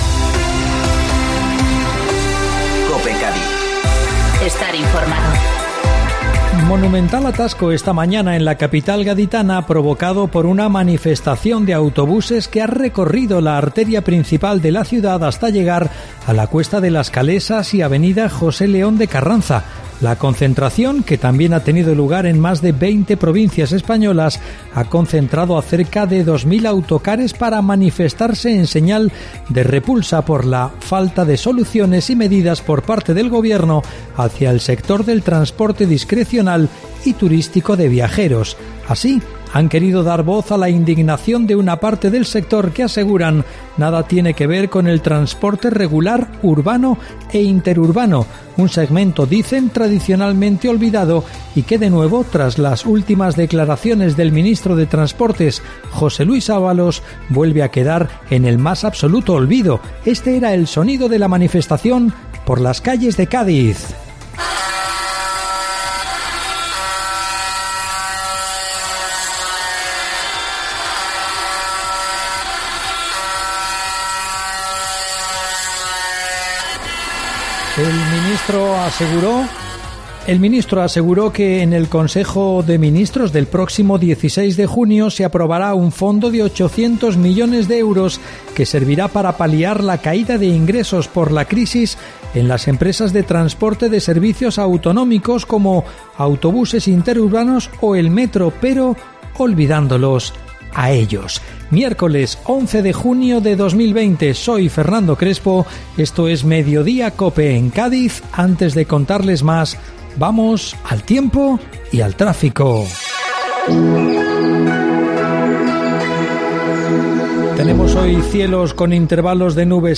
AUDIO: La información del día